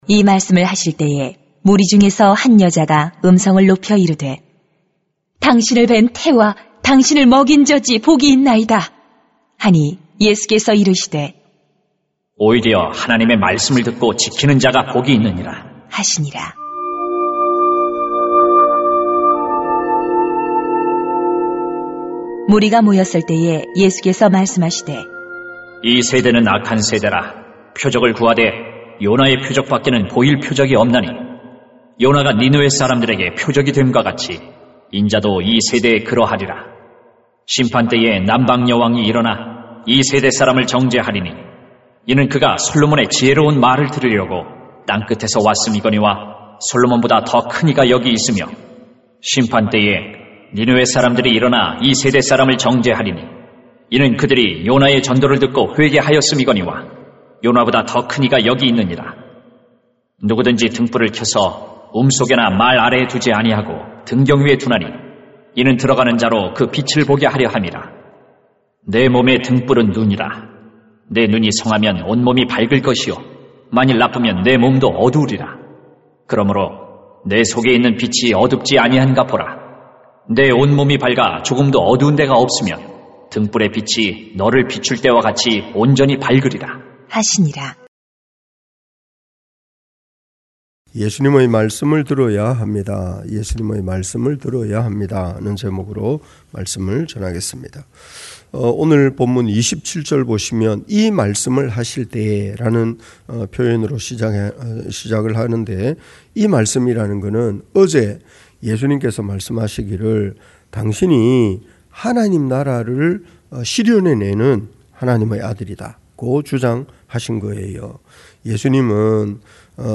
[눅 11:27-36] 예수님의 말씀을 들어야 합니다 > 새벽기도회 | 전주제자교회